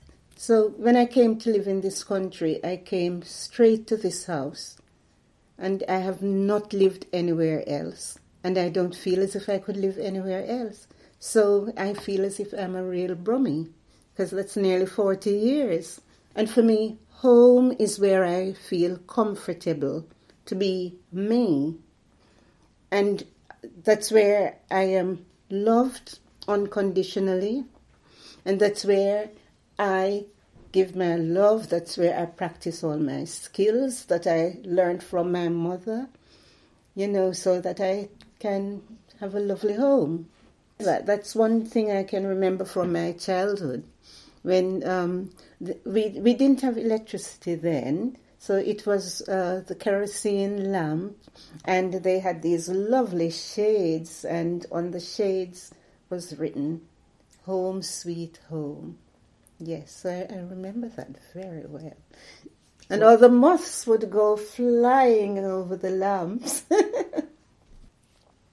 We worked with photographer Vanley Burke and older African-Caribbean women to create Home.